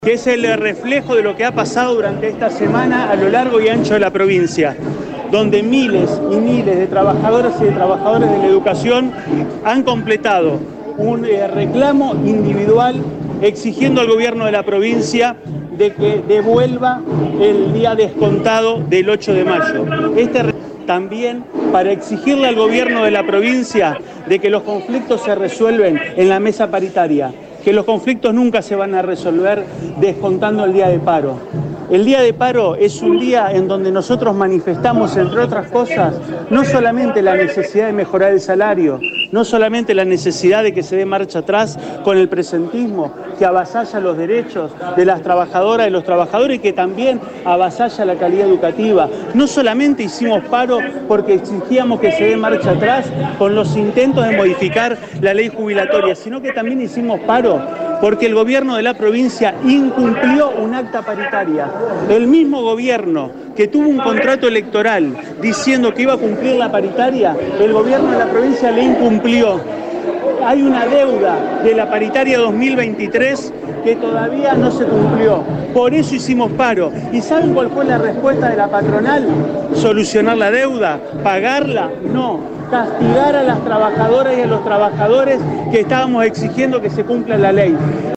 Desde el móvil de RADIO EME